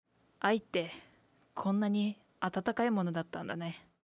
ボイス
女性